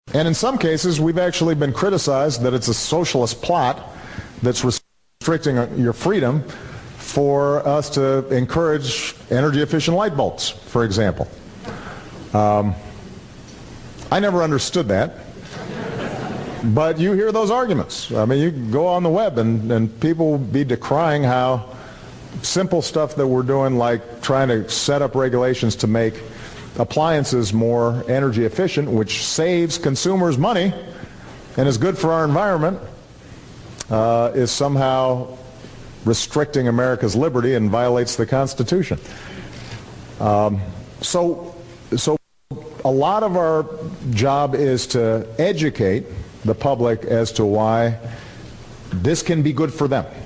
obama-on-cfls-and-smart-appliances.mp3